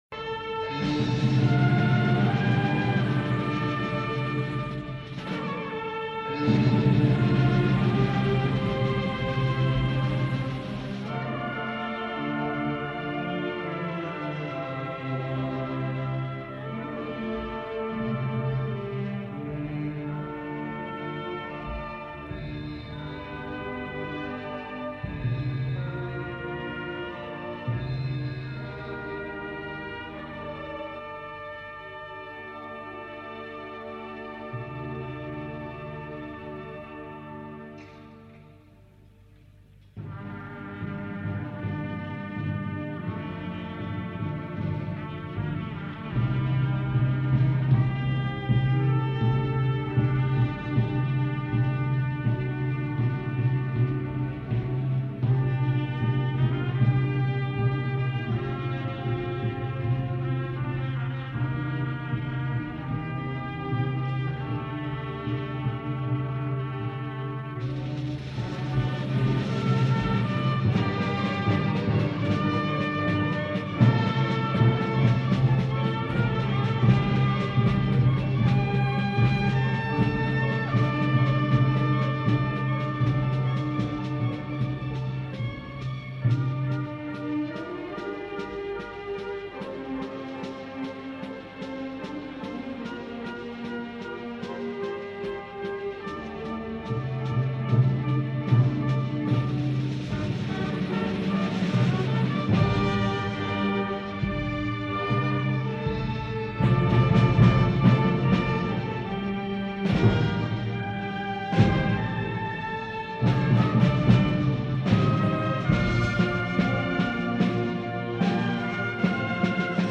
Concert de Sa Fira a l'Esglèsia de la Nostra Senyora de la Consolació